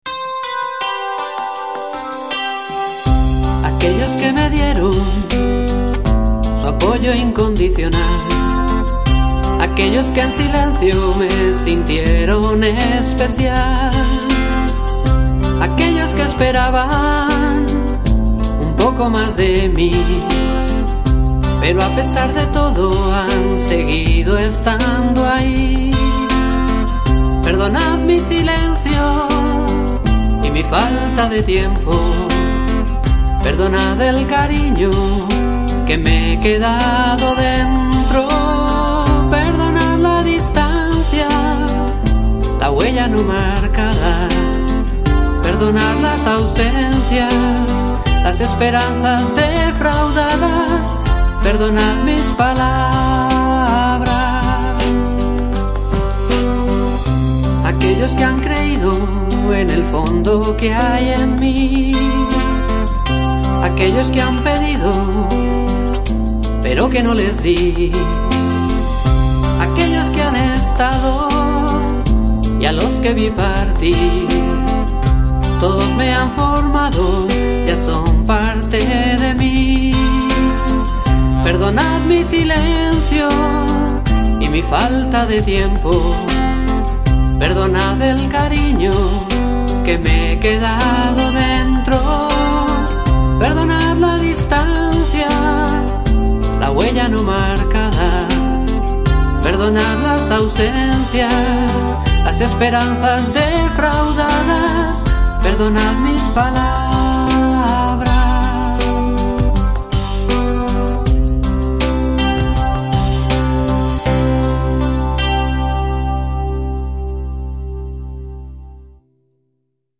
Cantautor madrileño